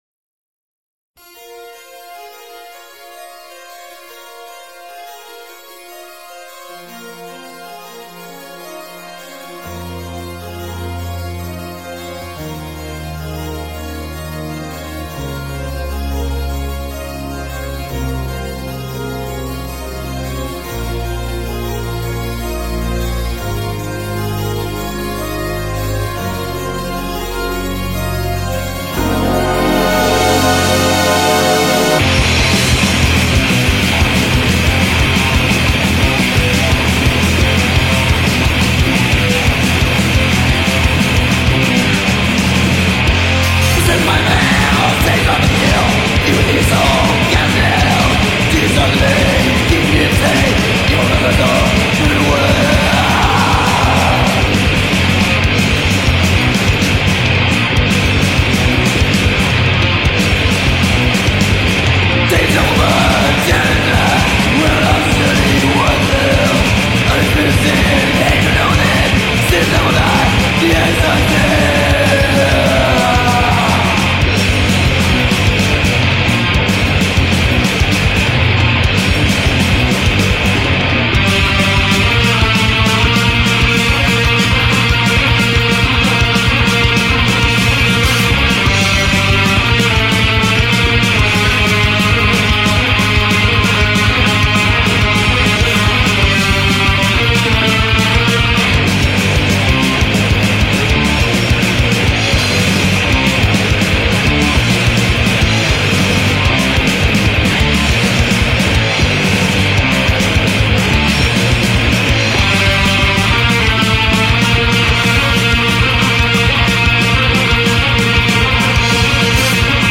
The first true death metal album.